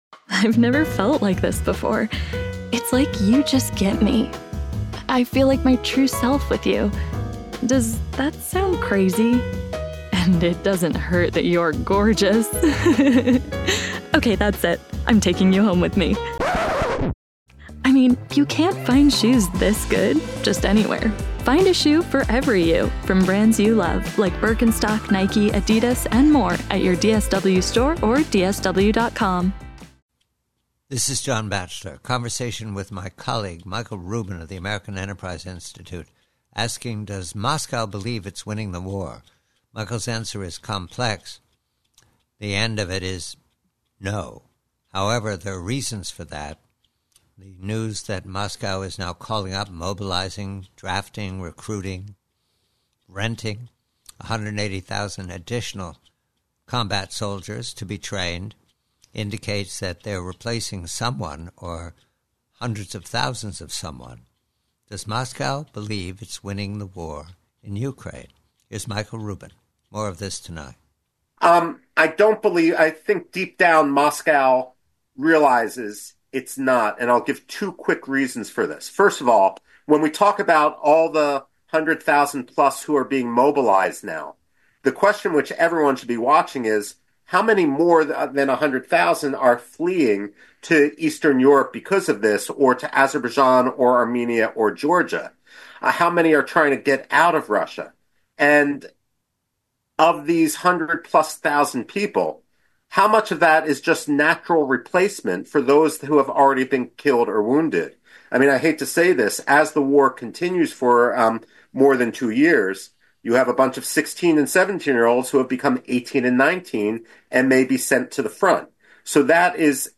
PREVIEW: RUSSIA: UKRAINE Conversation with colleague Michael Rubin of AEI regarding Moscow's call-up of an additional 180,000 recruits to fill the ranks for a new spring fighting season in Ukraine.